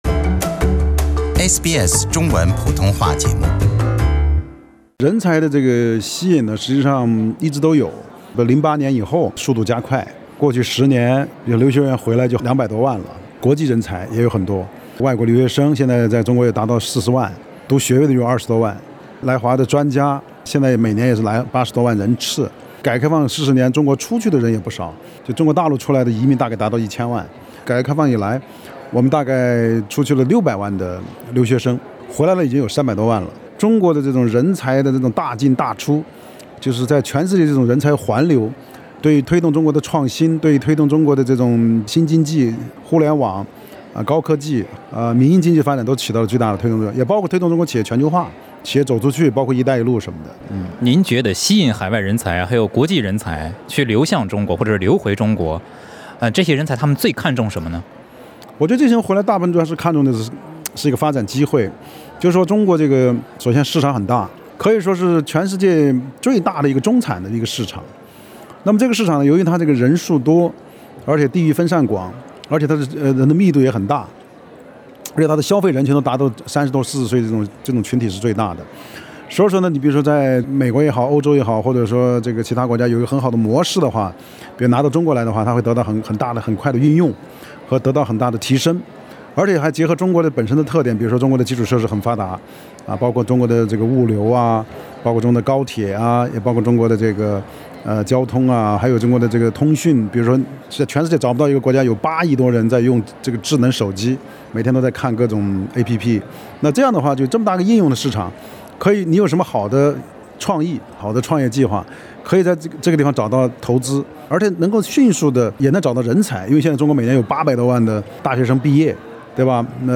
王辉耀教授（左一）接受SBS普通话节目采访 Source: sbs mandarin